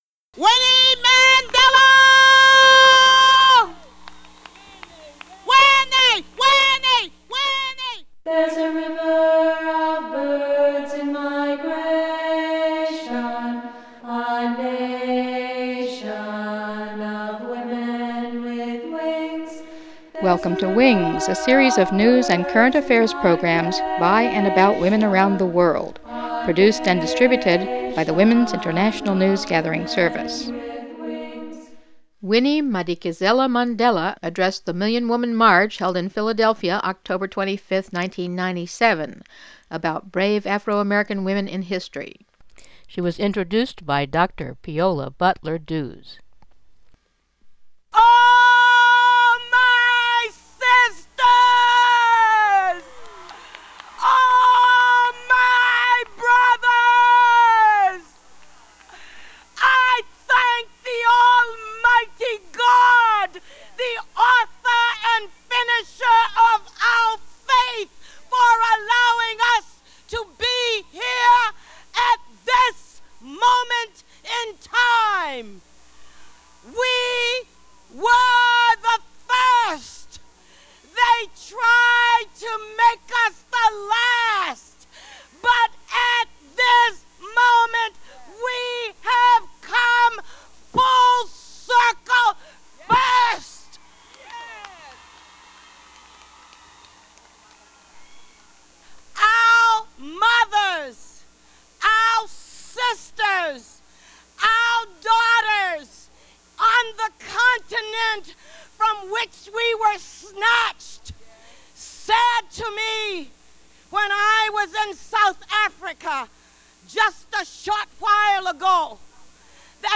WINGS #42-19 Winnie Mandela at Million Woman March